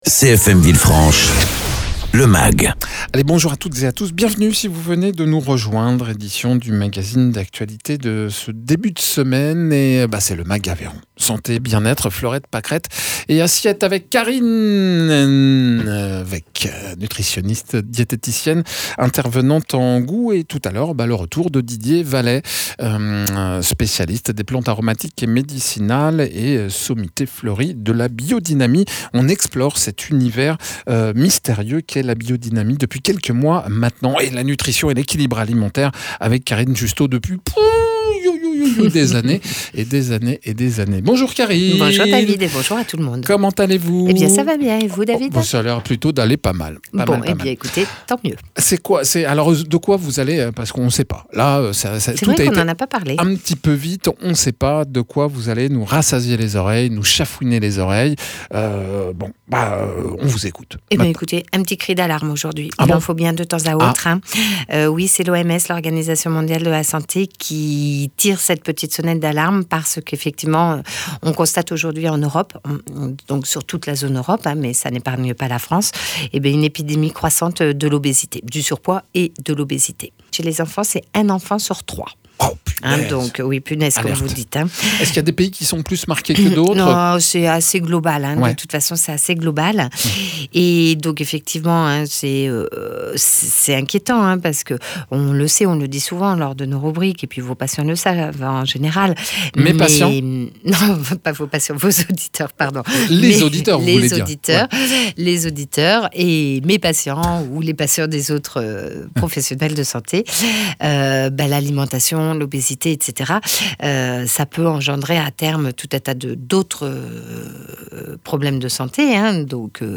L’OMS s’inquiète dans son nouveau rapport de cette épidémie qui n’a pas de frontière et que rien ne semble arrêter. Un rapport commenté par notre intervenante. Egalement dans ce mag, Le compost biodynamique.